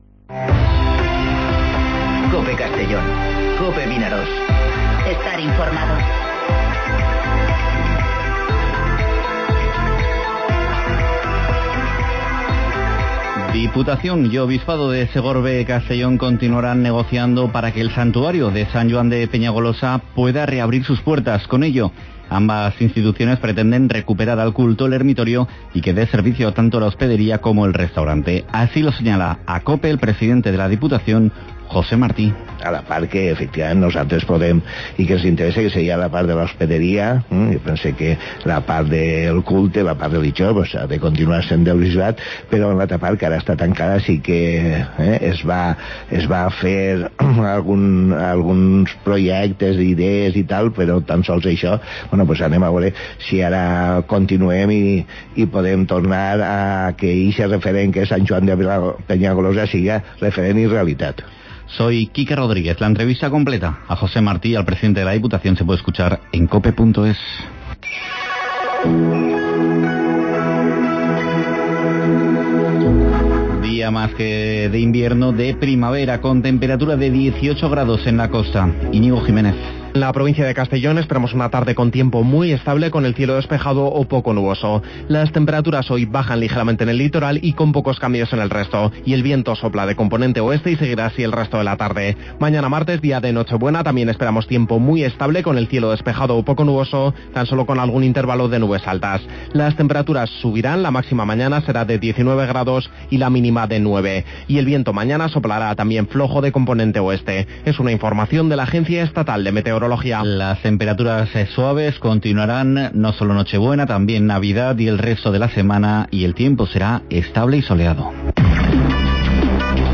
Informativo Mediodía COPE en Castellón (23/12/2019)